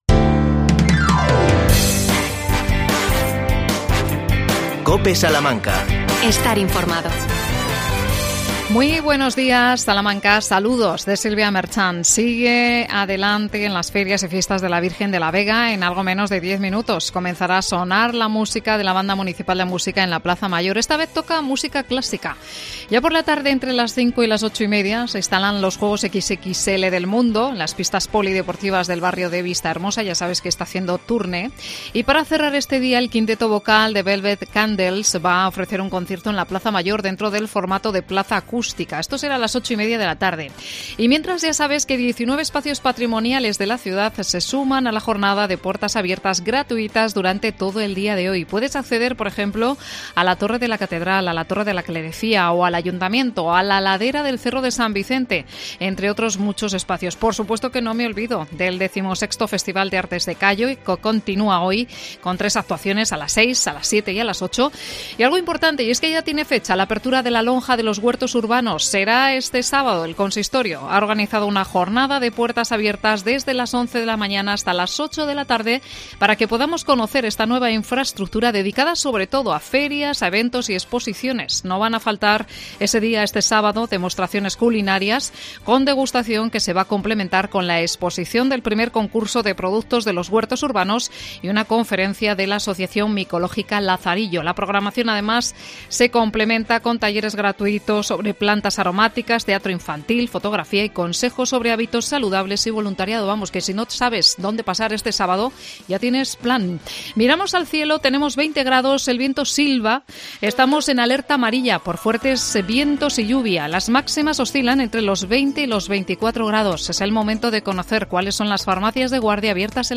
AUDIO: Entrevista a Almudena Parres, concejala de Participación Ciudadana. Presupuestos participativos para 2023.